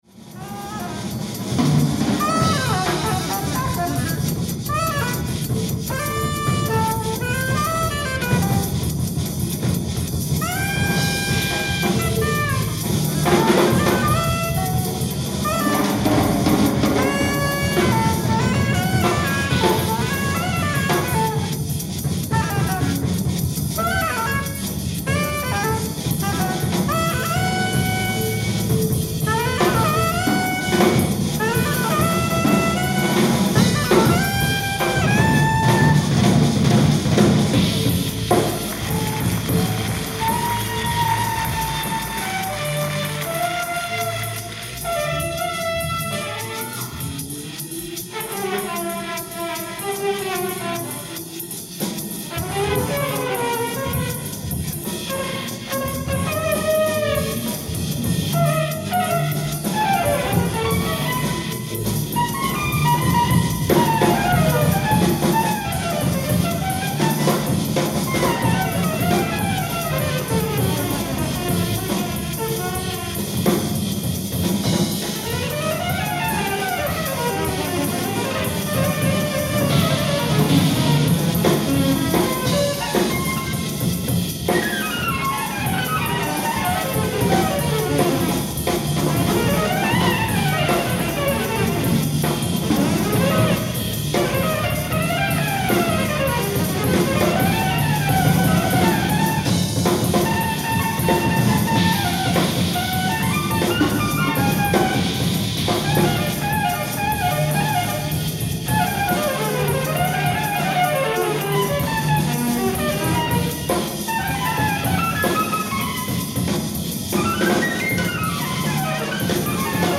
ライブ・アット・プロヴィデンス、ロードアイランド 05/02/1986
※試聴用に実際より音質を落としています。